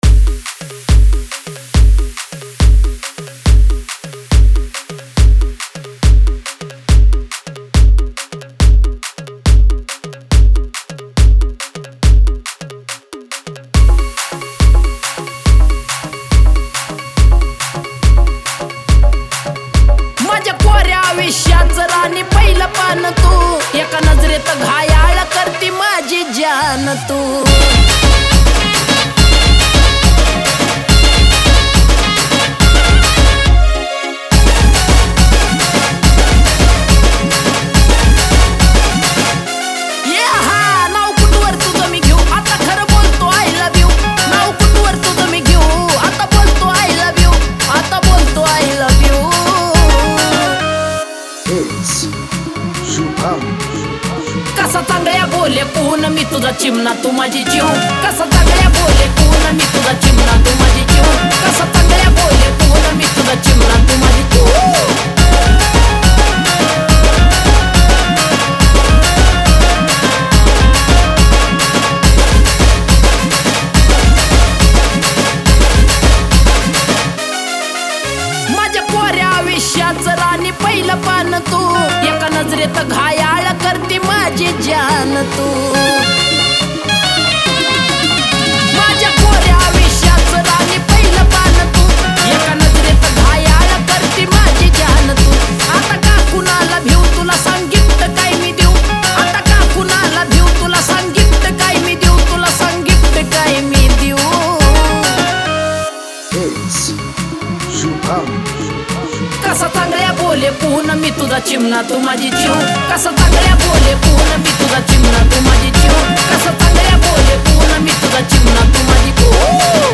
Marathi Dj Single 2025
Marathi DJ Album Update